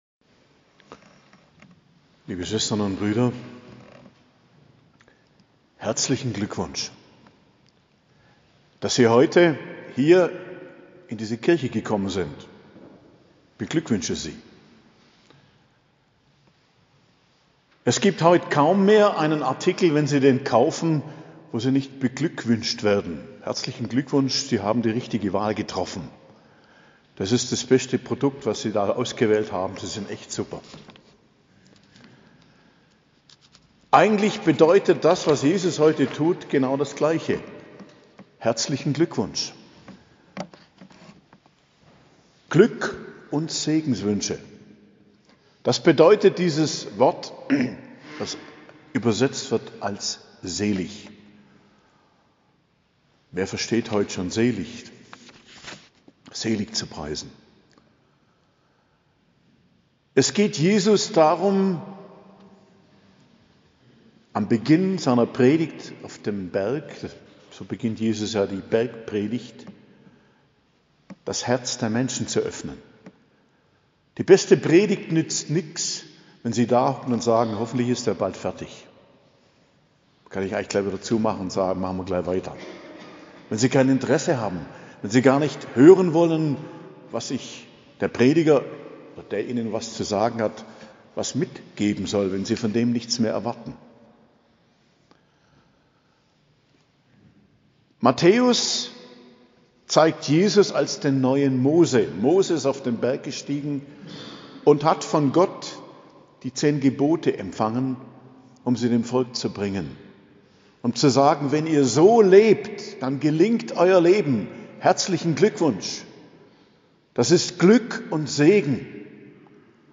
Predigt zum 4. Sonntag i.J., 29.01.2023